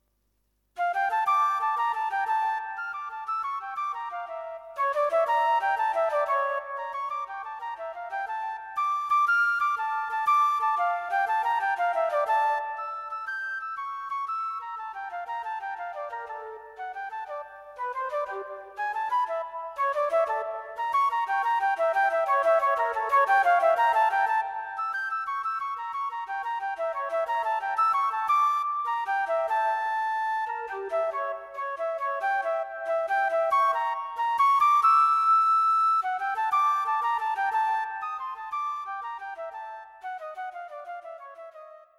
Four very different melodies for solo, unaccompanied flute.